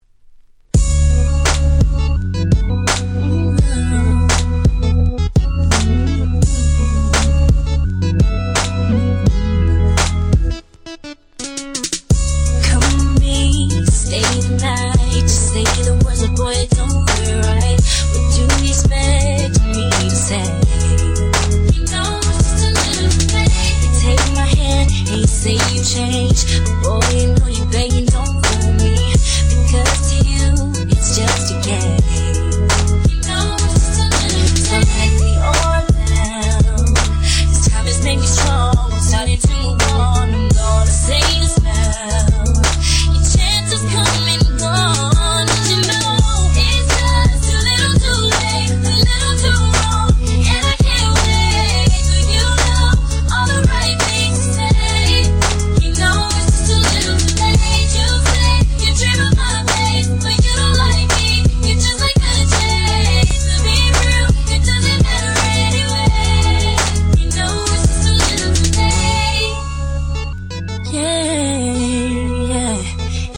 00's R&B